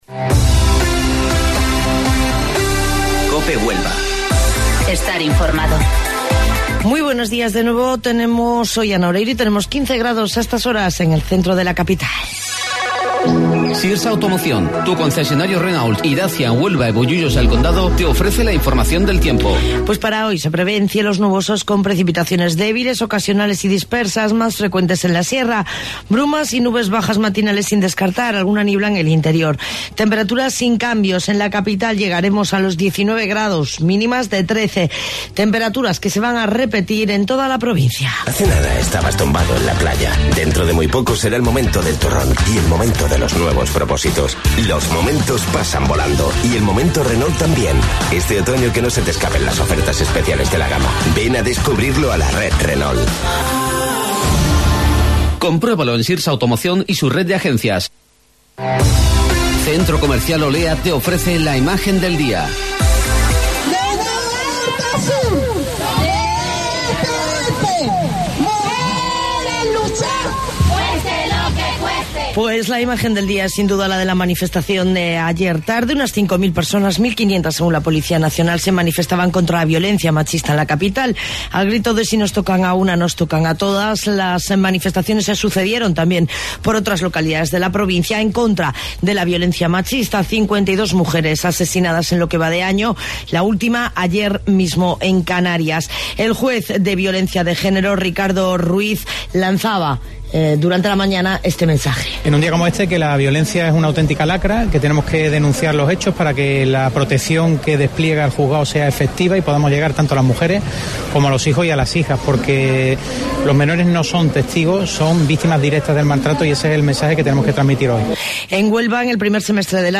AUDIO: Informativo Local 08:25 del 26 de Noviembre